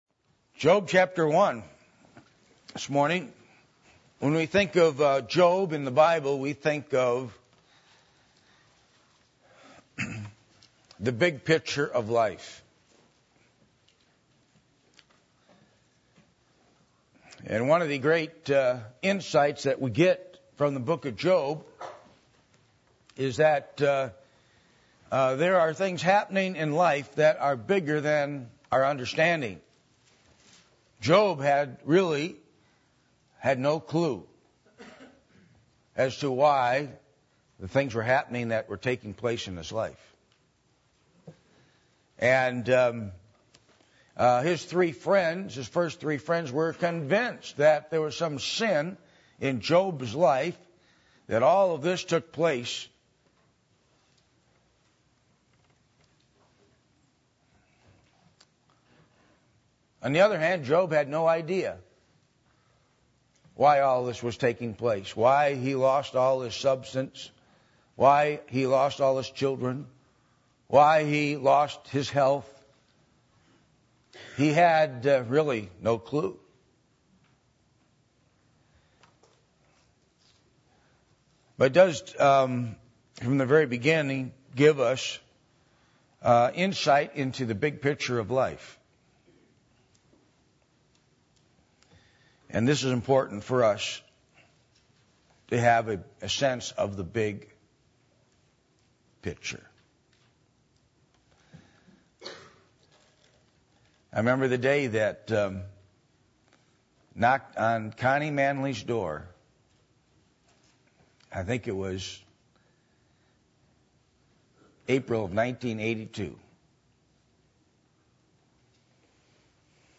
Passage: Job 1:1-22 Service Type: Sunday Morning %todo_render% « Christian Home Series